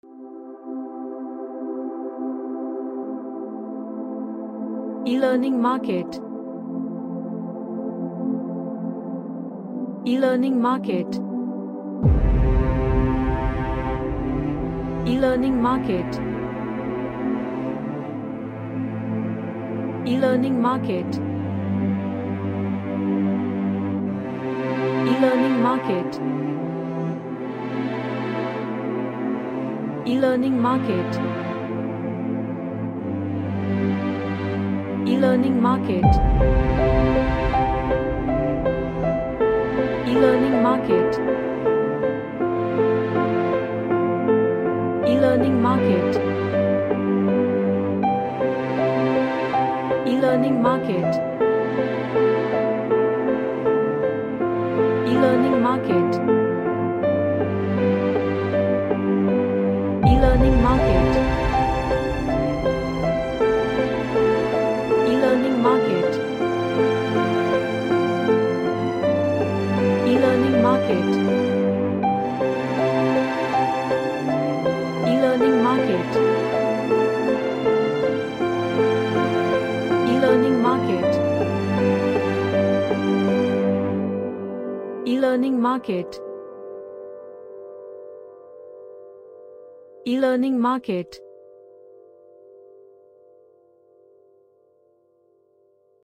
An emotional and sad Orchestral music
Emotional